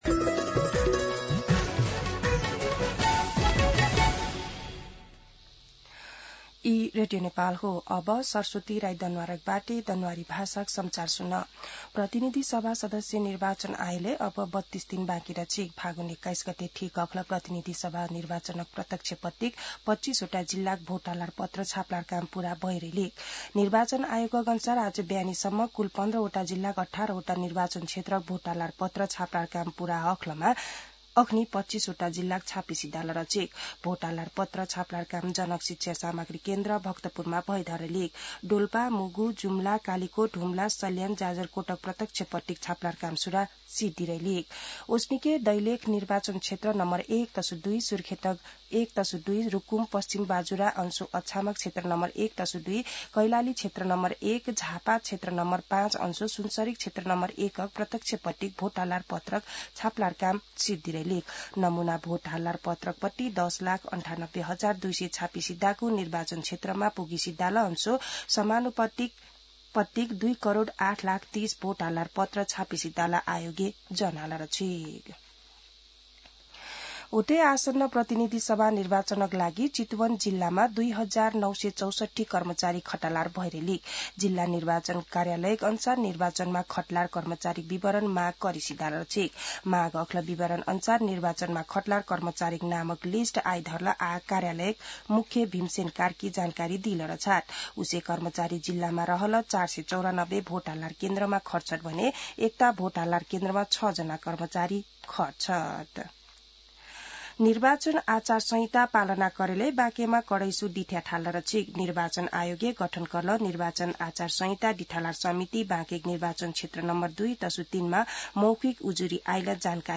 दनुवार भाषामा समाचार : १८ माघ , २०८२
Danuwar-News-18.mp3